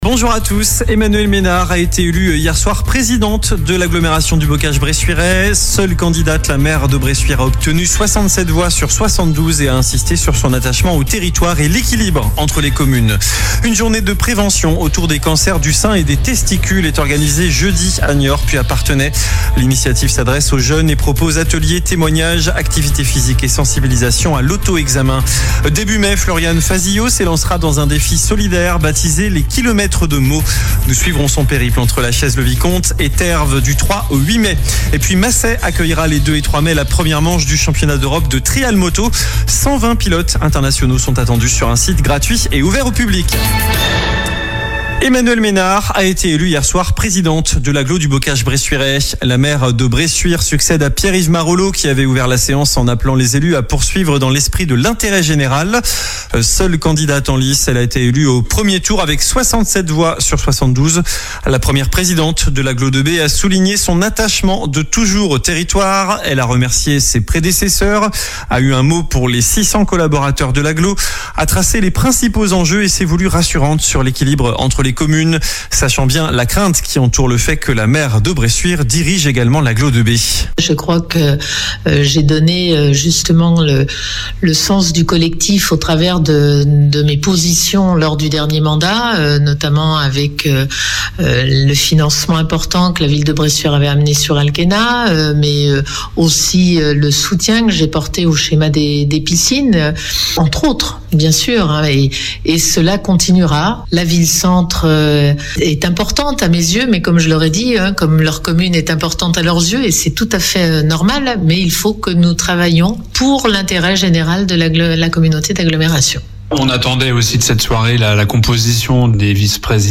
Journal du mercredi 15 avril (midi)